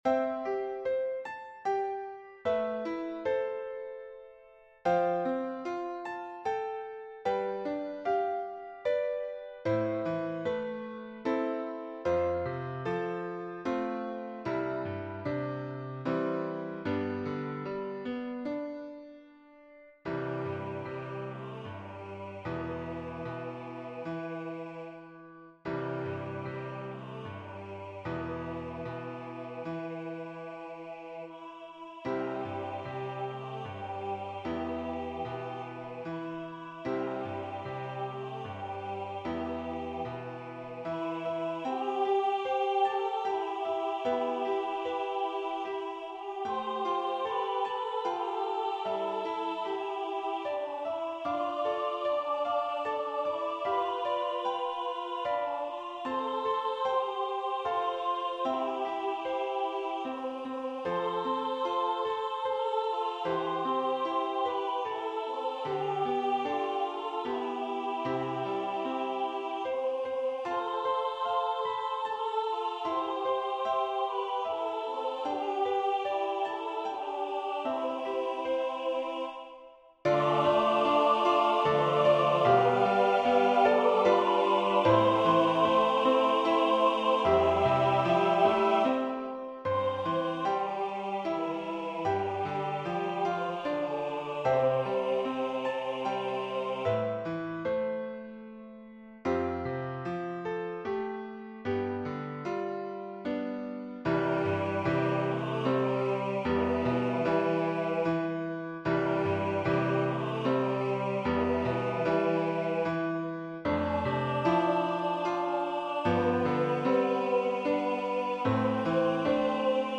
This choir arrangement for Silent Night includes a part for a soloist to sing Brahm's Lullaby (envision Mary singing the lullaby), as she is supported by women angel voices singing Silent Night.
Voicing/Instrumentation: SATB , Vocal Solo We also have other 122 arrangements of " Silent Night ".
Choir with Soloist or Optional Soloist